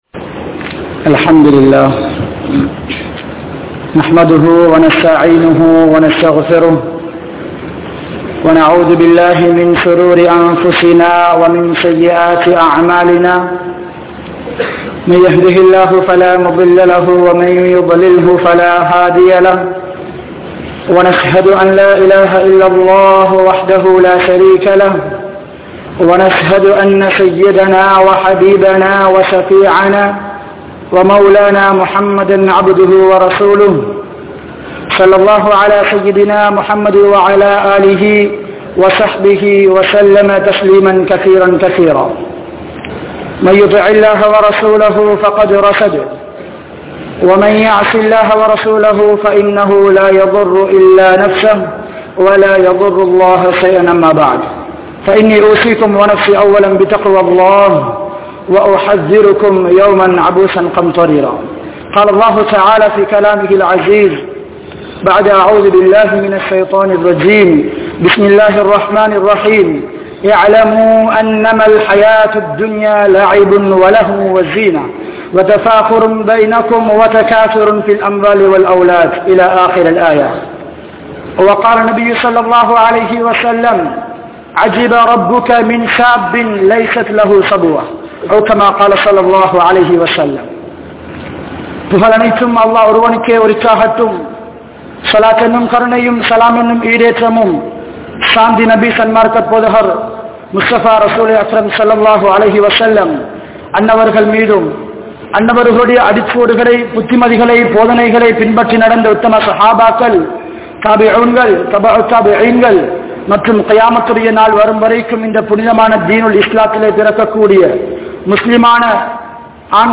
Seeralium Indraya Vaalifarhale! (சீரழியும் இன்றைய வாலிபர்களே!) | Audio Bayans | All Ceylon Muslim Youth Community | Addalaichenai